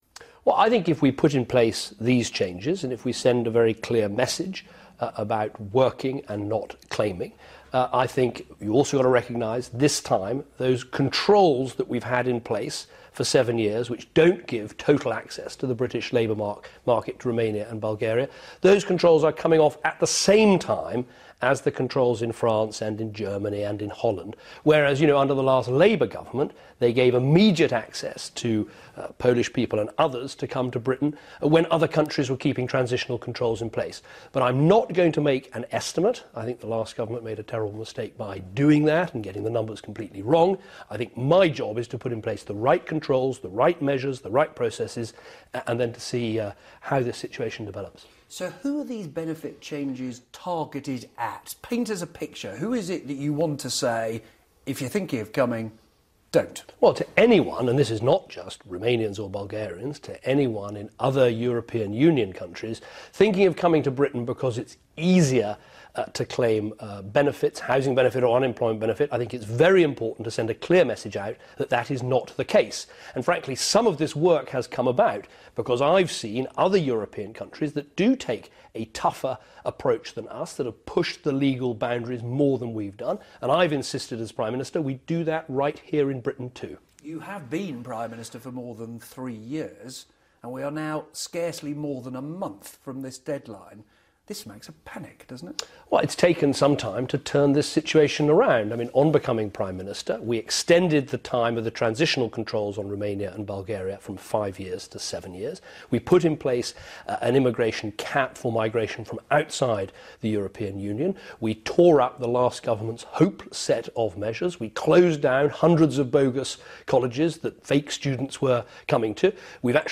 Interview with Nick Robinson, 27 November 2013